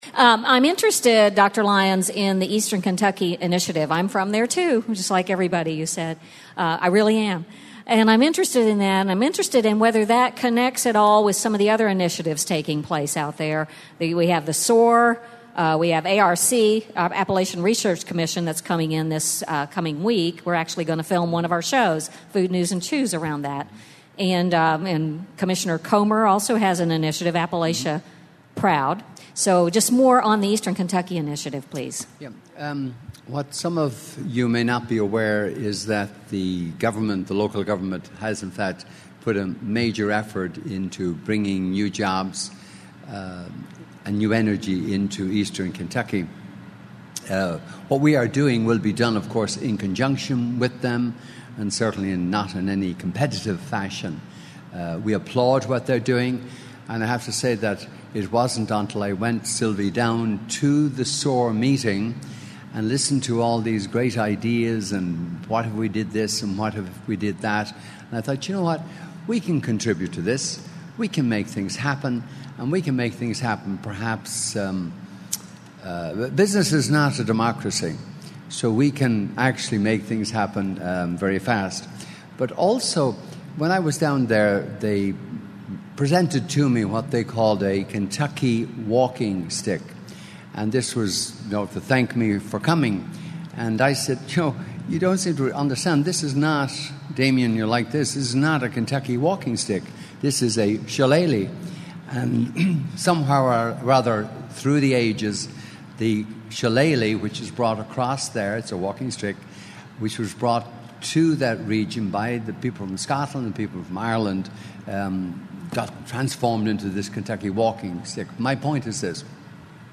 Alltech Press Conference Q&A: